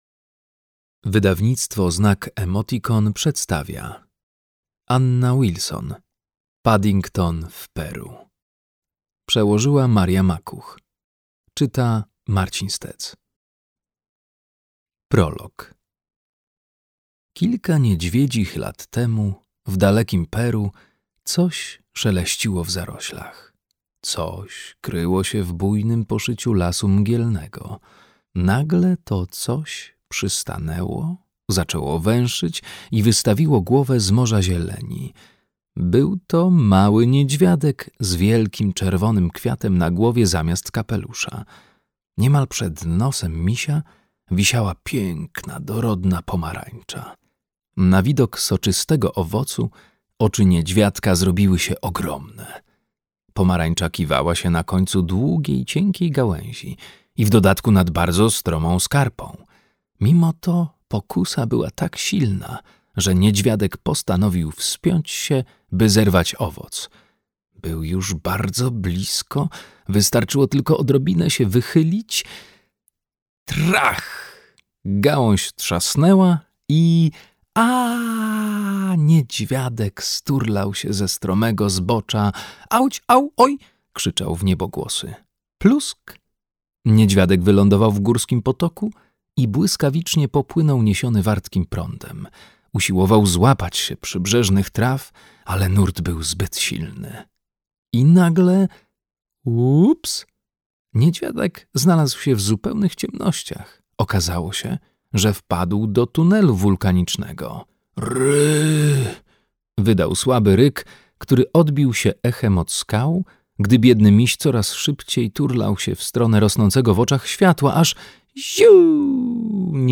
Paddington w Peru. Opowieść filmowa - Wilson Anna - audiobook + książka